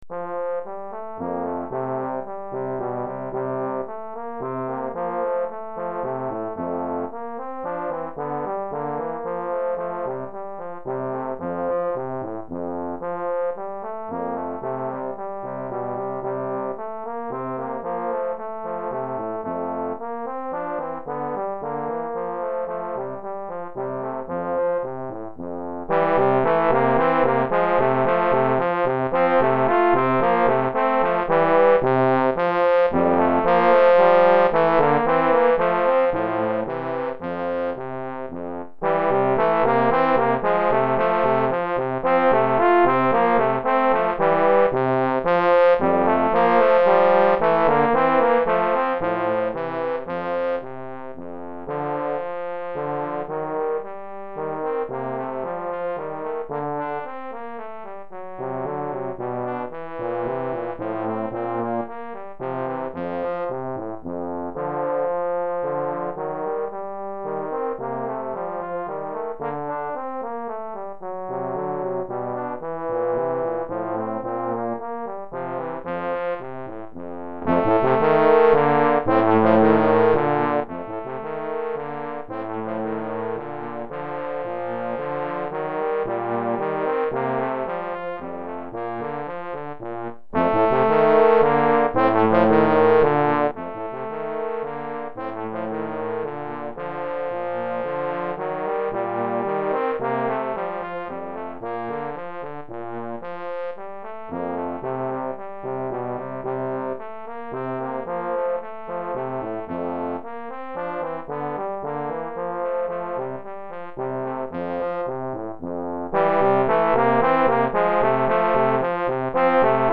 Répertoire pour Trombone - 2 Trombones